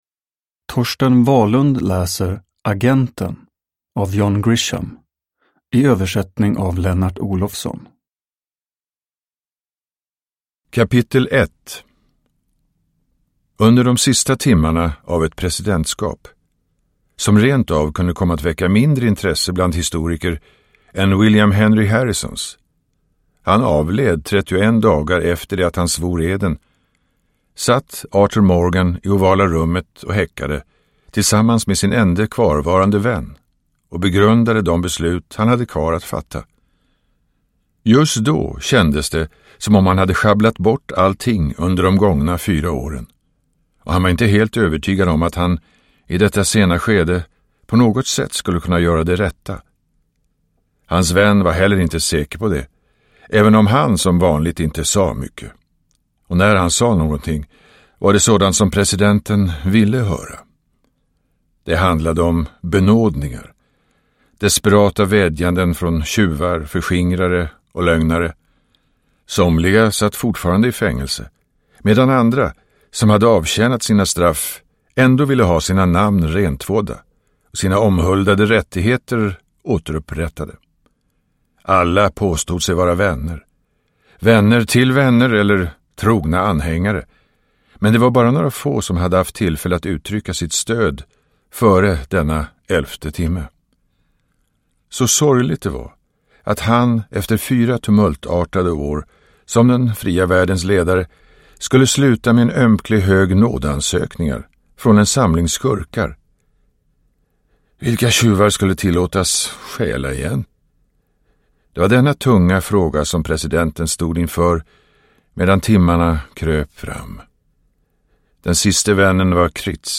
Agenten – Ljudbok – Laddas ner
Uppläsare: Torsten Wahlund